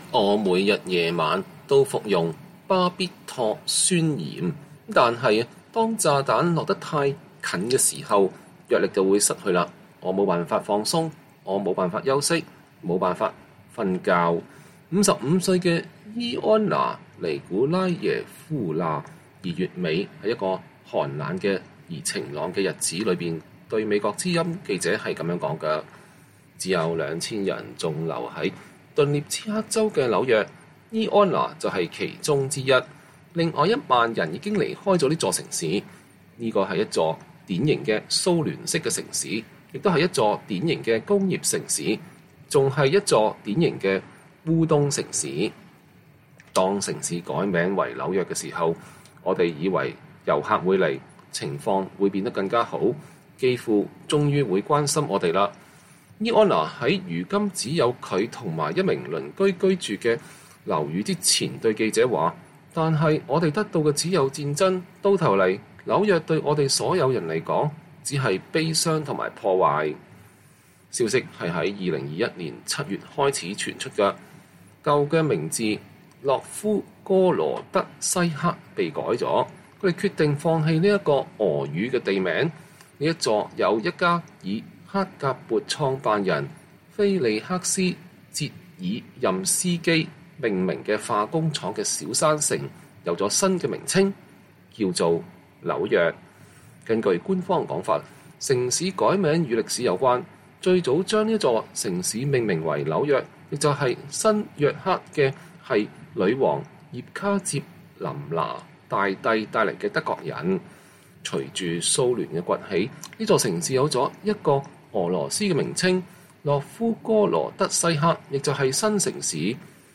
不過這個紐約是烏克蘭頓涅茨克州的紐約，昔日名為諾夫戈羅德西克。城市的街道空空蕩蕩，建築物處在半廢棄狀態，地窖一片漆黑，這使得炮聲仿佛更加響亮，甚至更加嚇人。
炮火似乎從未止息。